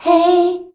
HEY     F.wav